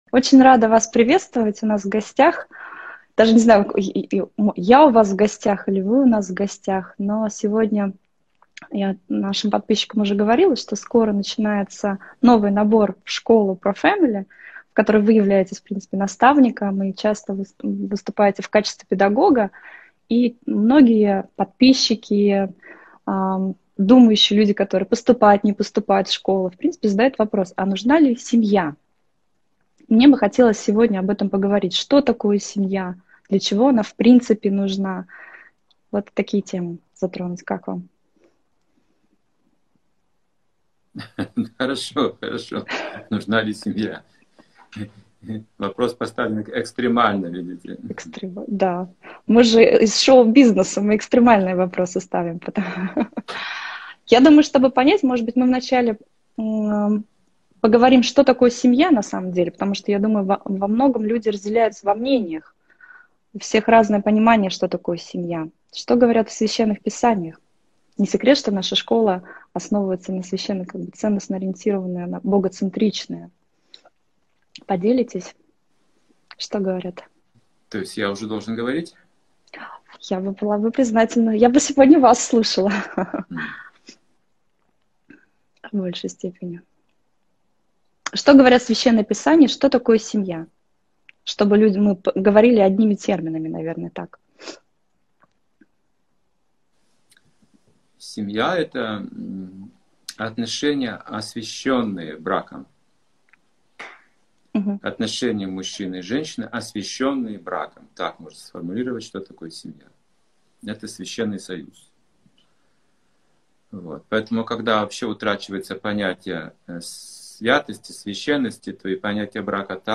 Алматы, Беседа